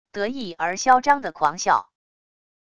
得意而嚣张的狂笑wav音频